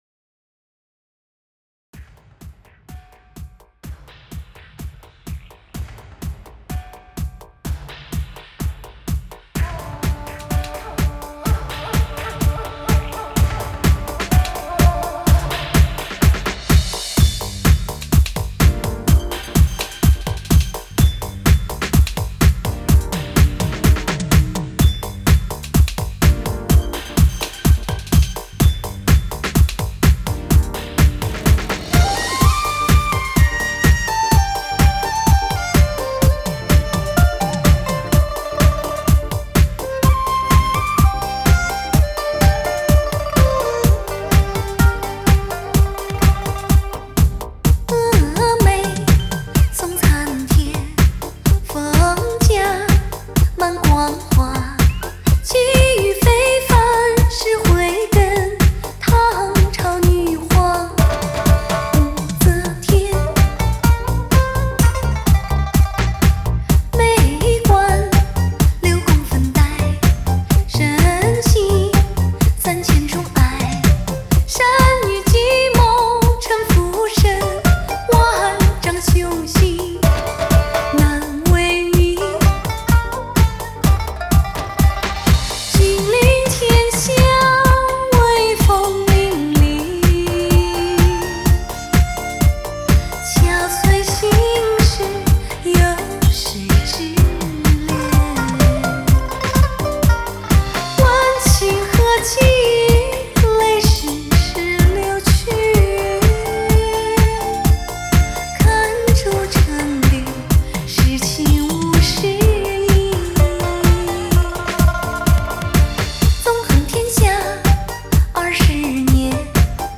舞曲也是很好听的，节奏也很好，下载了